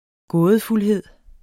Udtale [ ˈgɔːðəfulˌheðˀ ]